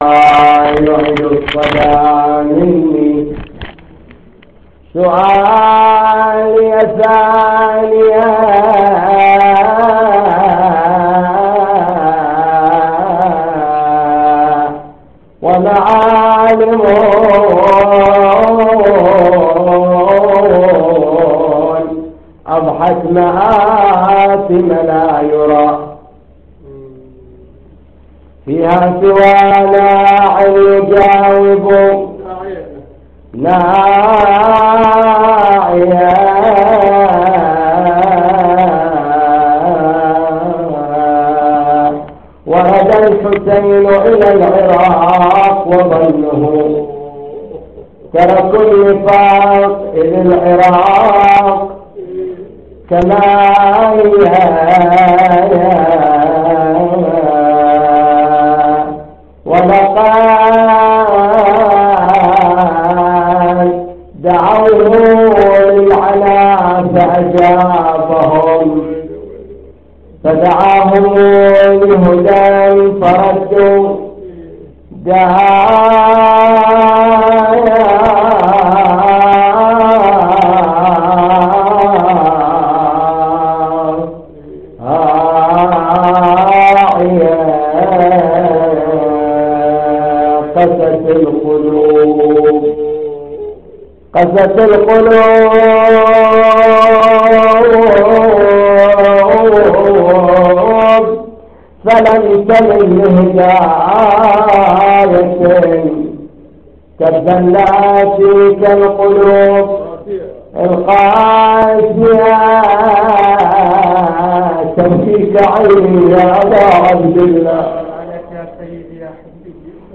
مجلس حسيني